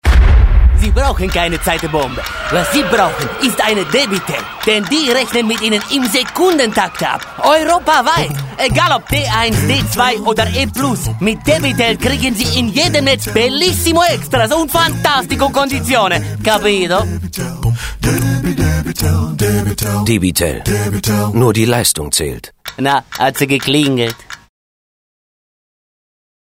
Funkspot Debitel
Debitel Zeitbombe Funkspot.mp3